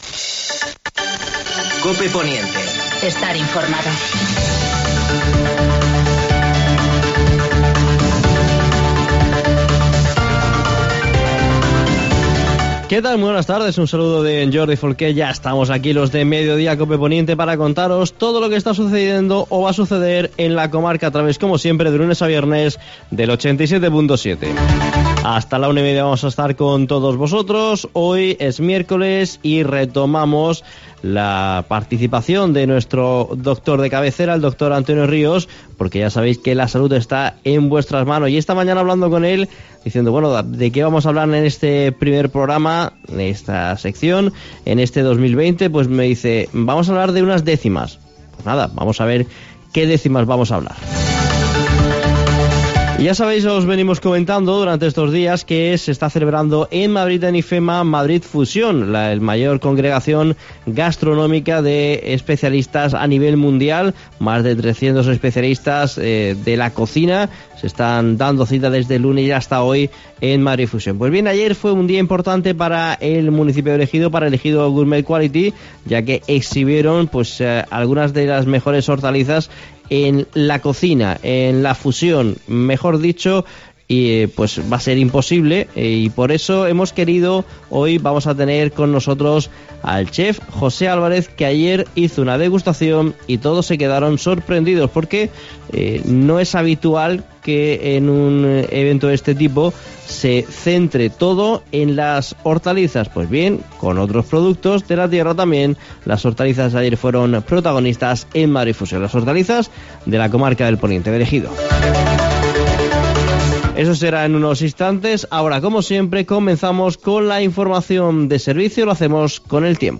Actualidad en el Poniente. Entrevista